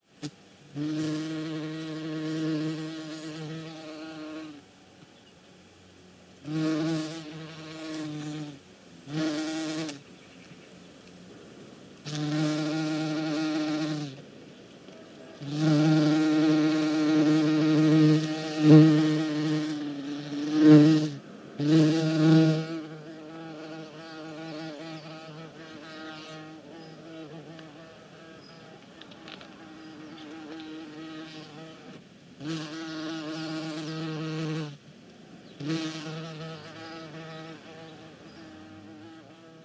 На этой странице собраны разнообразные звуки пчел: от одиночного жужжания до гула целого роя.
Жужжание пчелы у цветка